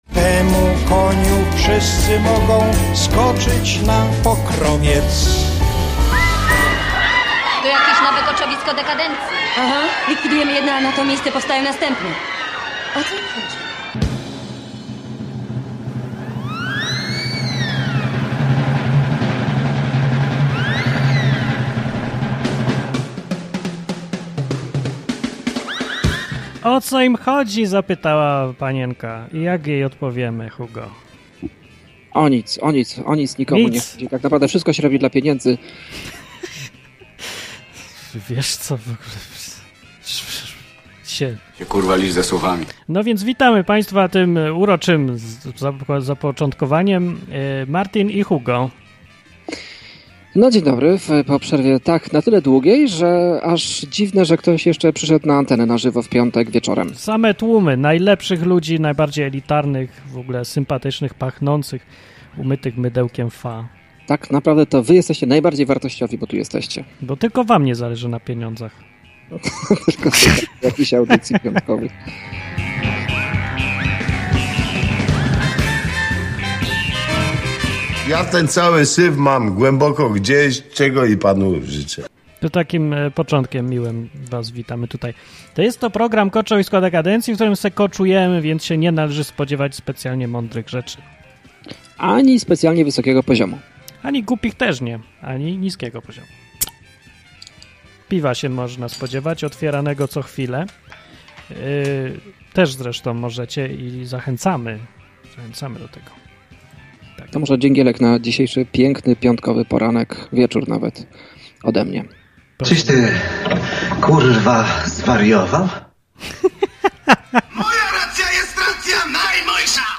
Koczowisko Dekandencji to dwu-radiowa audycja, w której od luzu, sarkazmu i ironii wióry lecą.